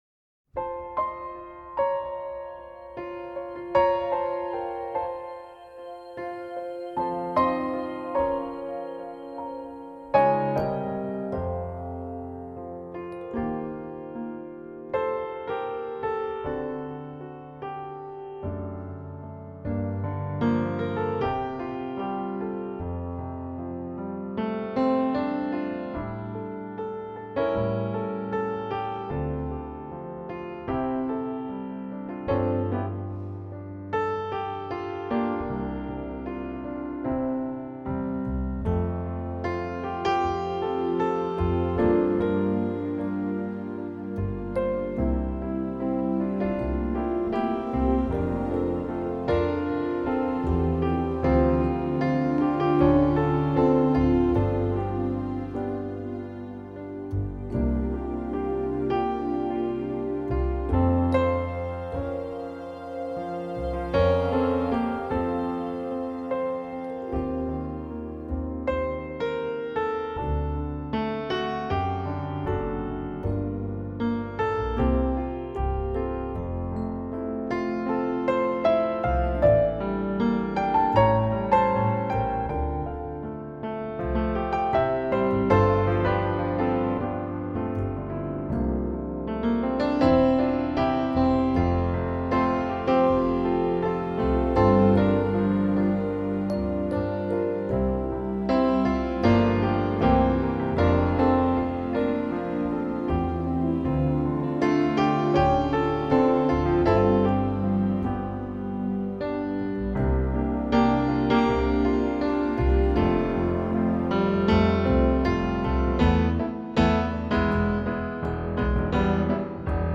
Genre: New Age, Instrumental, Piano.